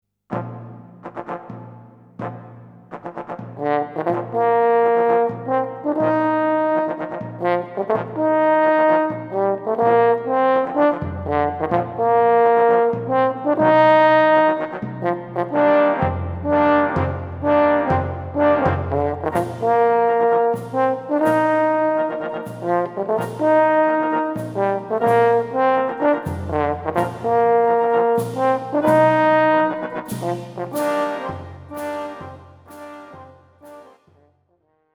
ホルン+ピアノ
輸入フレンチホルン・ソロ